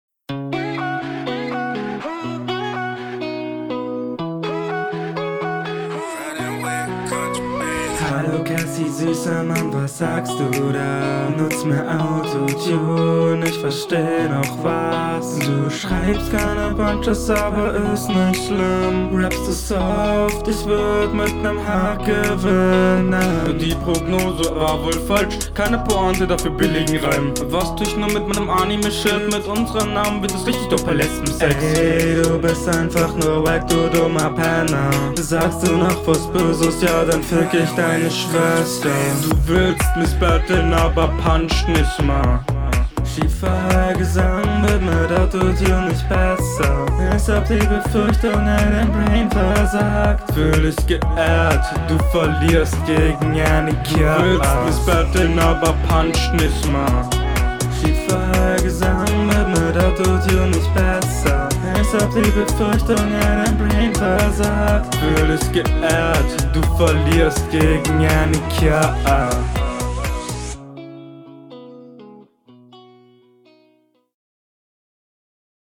Flow: Der Flow wirkt viel gestolperter.